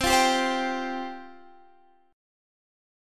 Csus4 Chord (page 3)
Listen to Csus4 strummed